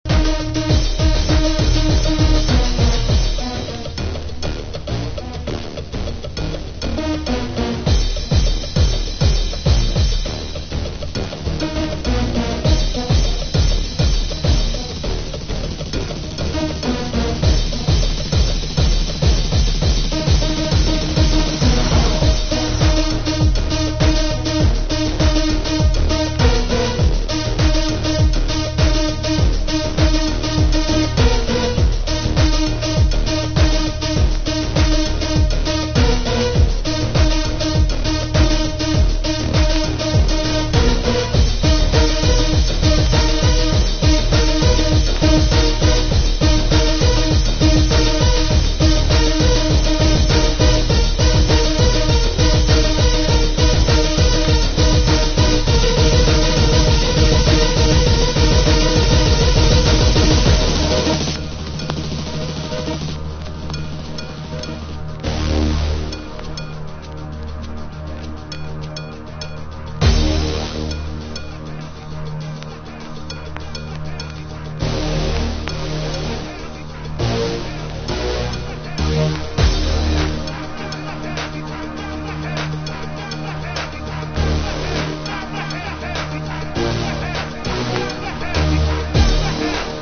• HOUSE